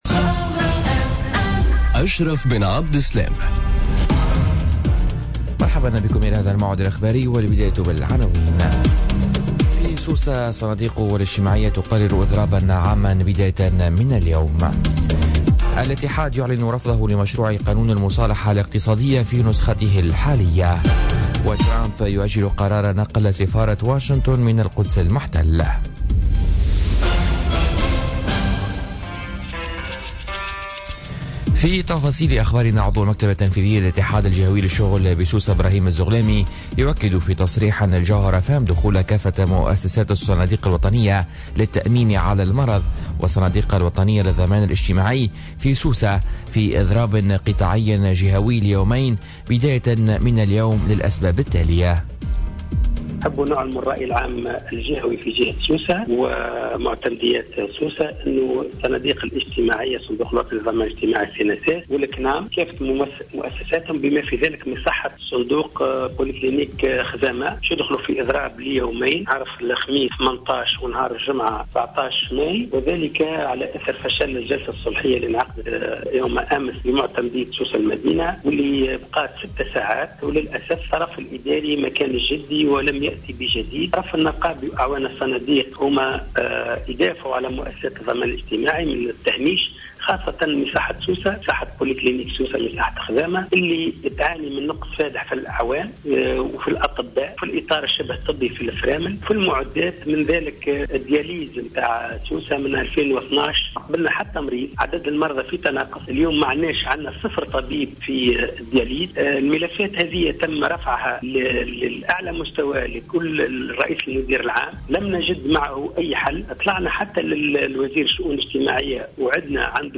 نشرة أخبار السابعة صباحا ليوم الخميس 18 ماي 2017